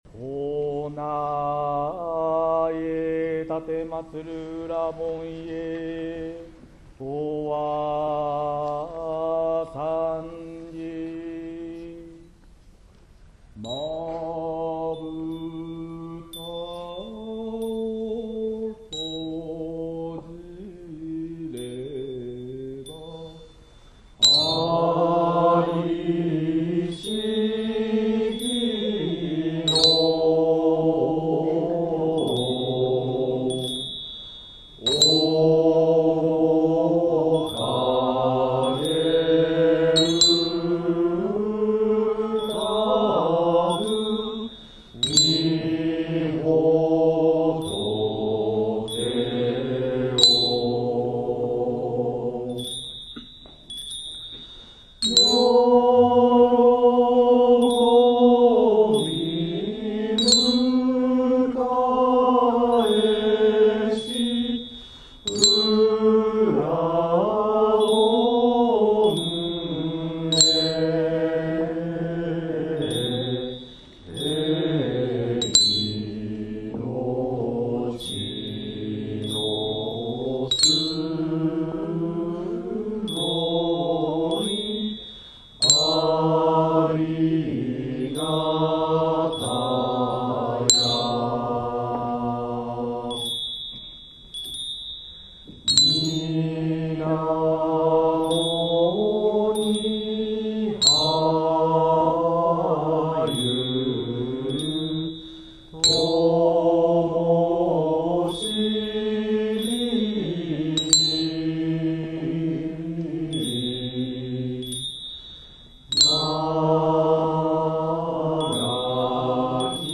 奉詠の始まりは多少声が小さかったものの、会員全員が大きな声で元気よくお唱えして、沢山の拍手をいただくことができました。
♪本番でお唱えしました音源です♪